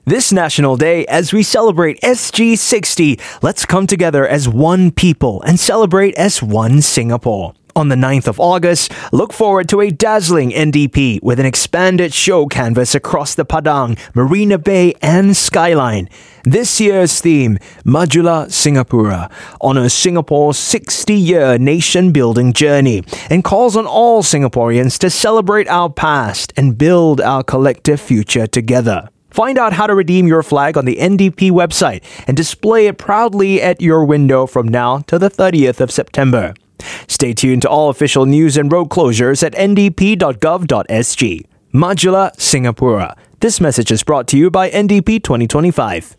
Voice Samples: NDP 2025 (Clean VO)
male